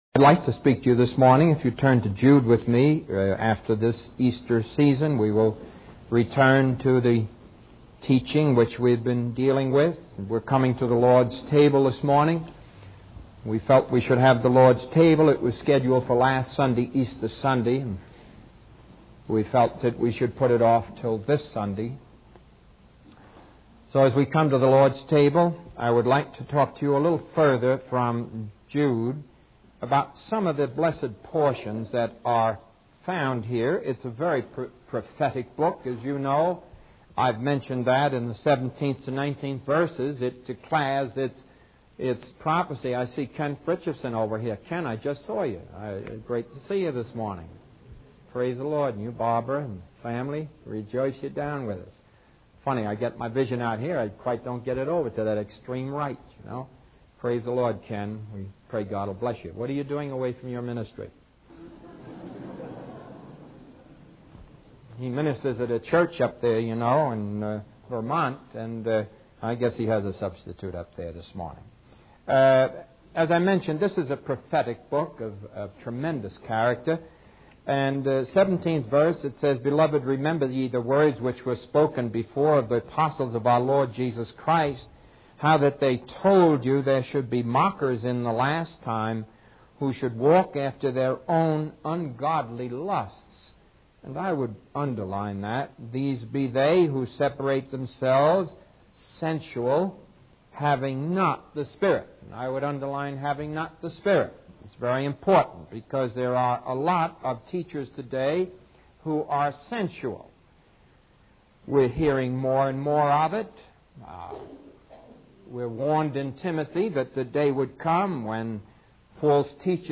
In this sermon, the speaker discusses the book of Jude, emphasizing the warning about mockers in the last days who will follow their own ungodly desires. He highlights the importance of recognizing that God only accepts one offering, and that good works alone are not enough for salvation. The speaker also references the story of Abel and Cain to illustrate the difference between true and false religion.